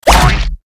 audio: Converted sound effects
AA_drop_boat_cog.ogg